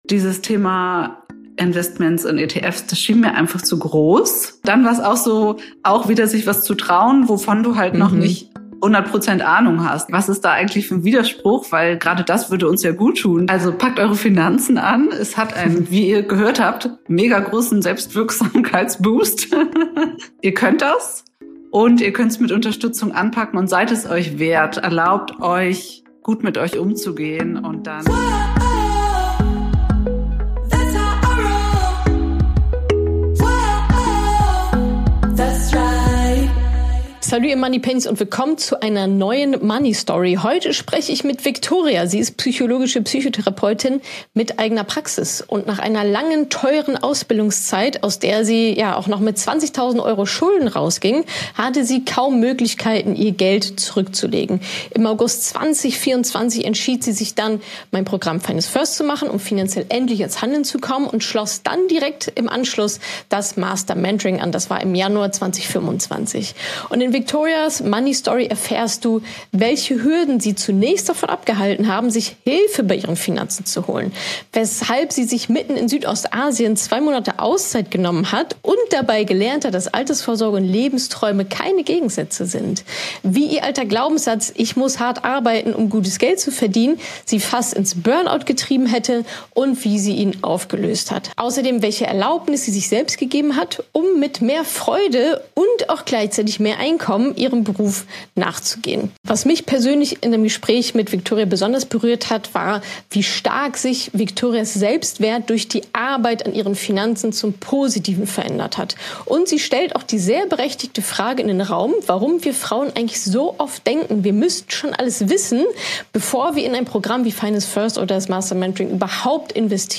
Interview für "Eltern" Folgt mir auf Instagram und Facebook!